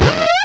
cry_not_stunky.aif